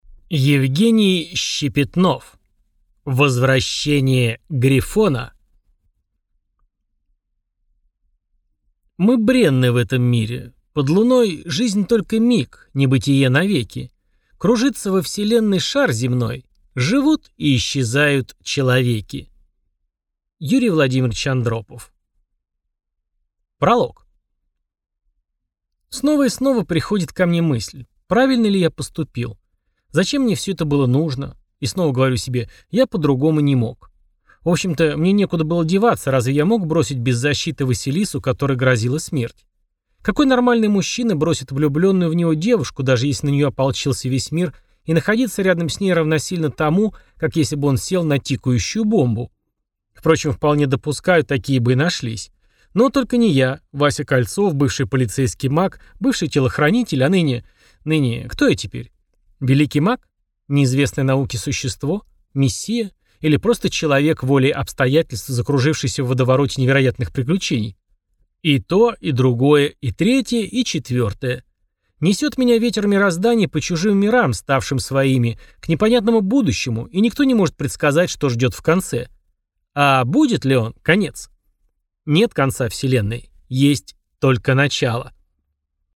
Аудиокнига Возвращение Грифона | Библиотека аудиокниг